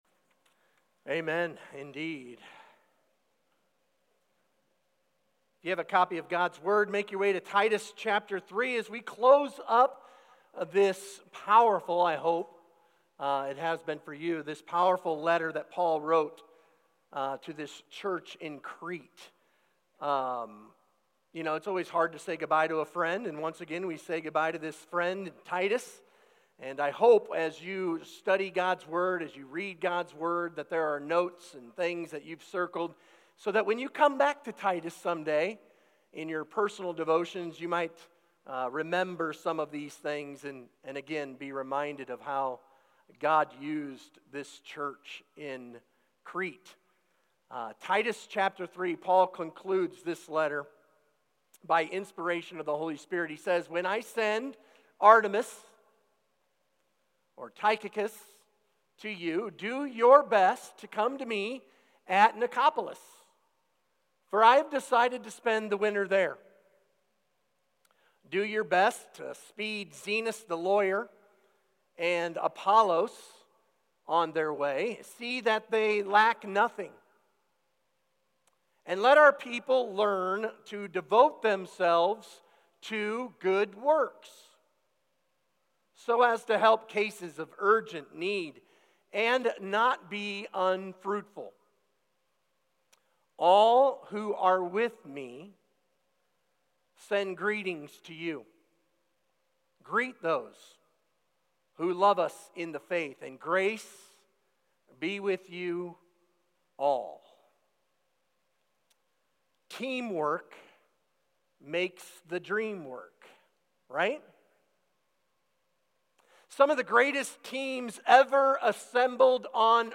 Sermon Discussion: Read the Passage (Titus 3:12–15).